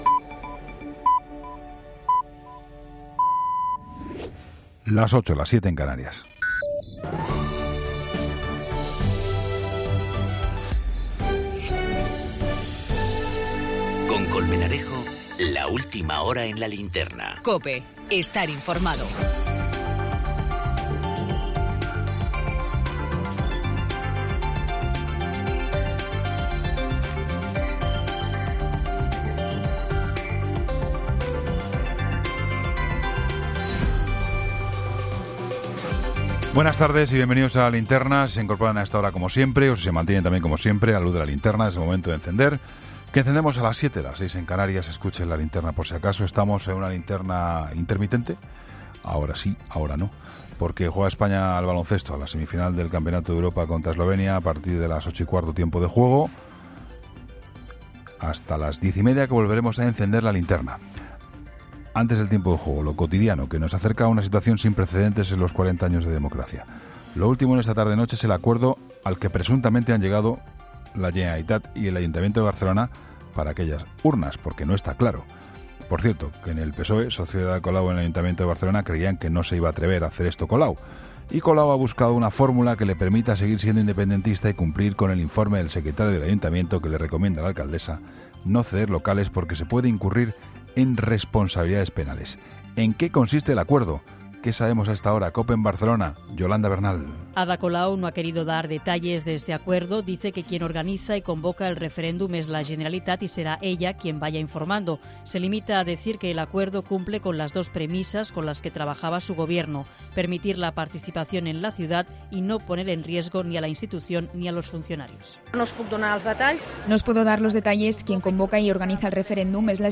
El acuerdo al que han llegado Colau y Puigdemont para que haya urnas el 1-O en Barcelona, en el análisis de Juan Pablo Colmenarejo a las 20.00 horas.